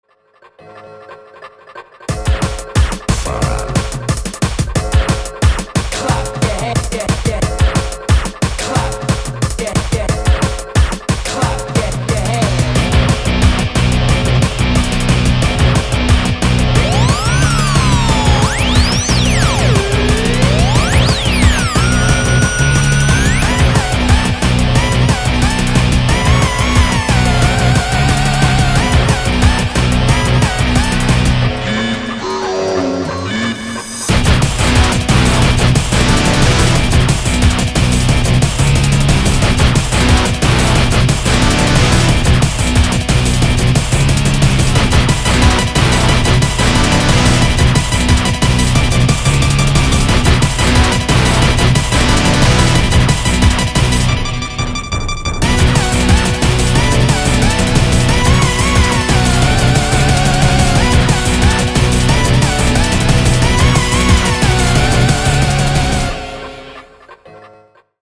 • Качество: 224, Stereo
Драйвовые
саундтреки
электрогитара
эпичные